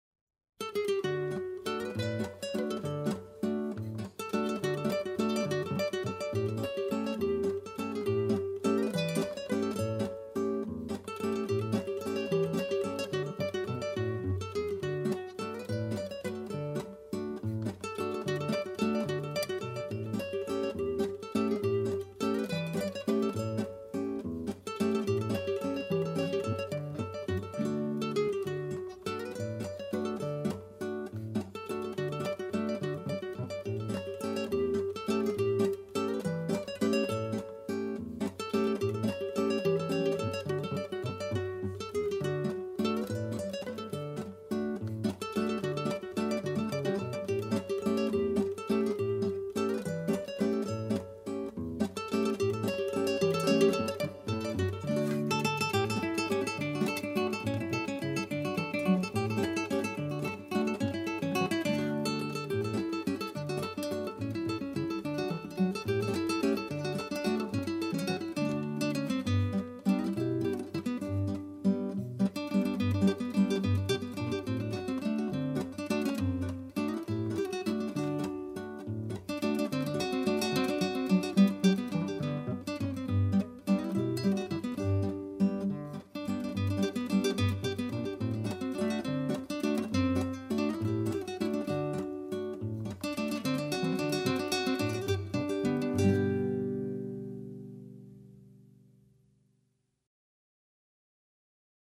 Boarisch: